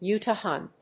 音節U・tah・an 発音記号・読み方
/júːtɑː(ə)n(米国英語)/